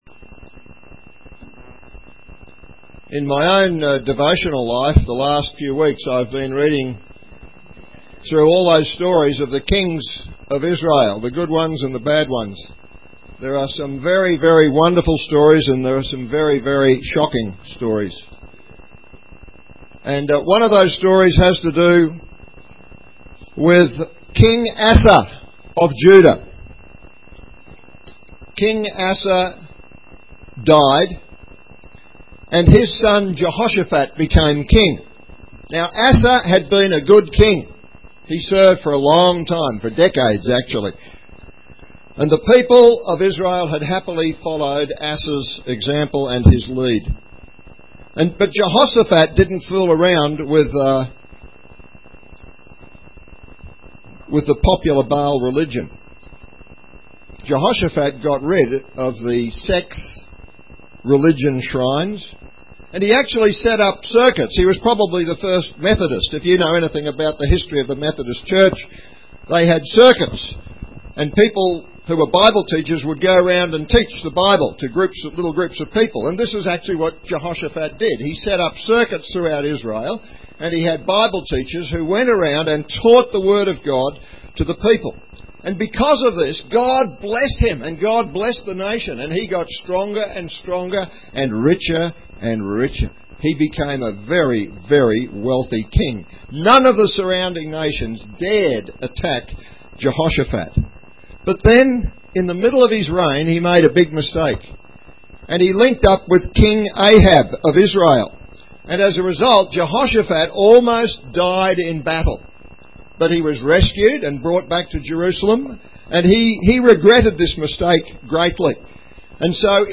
Categories Sermon Tags galatians